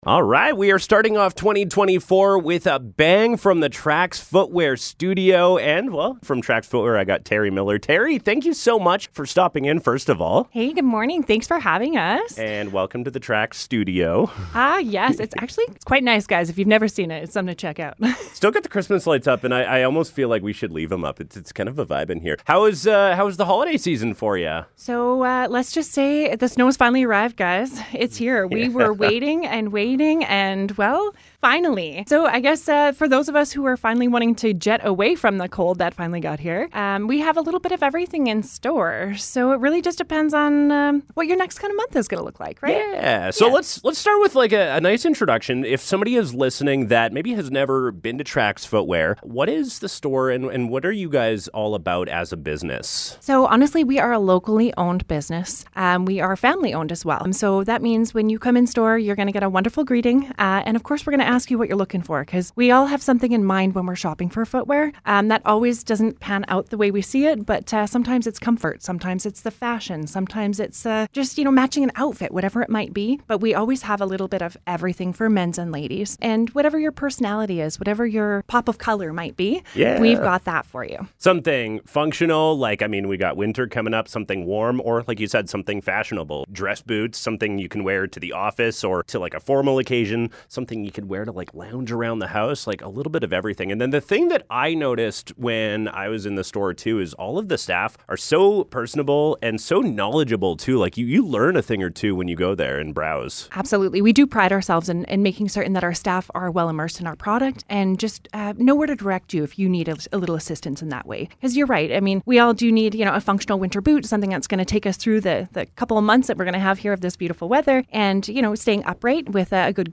Inteview